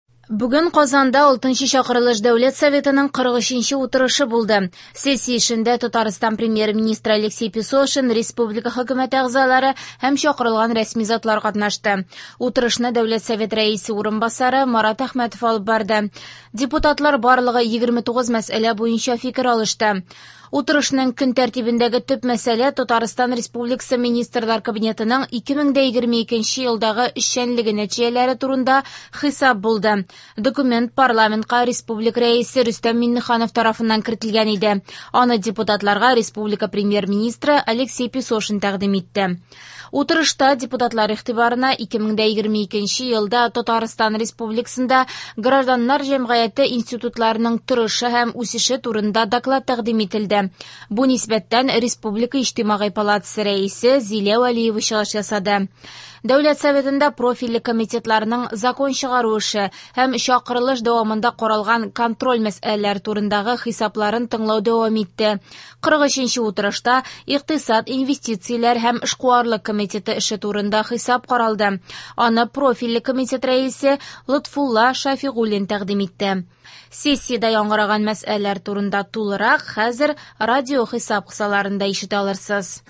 В эфире специальный информационный выпуск, посвященный 43 заседанию Государственного Совета Республики Татарстан 6-го созыва.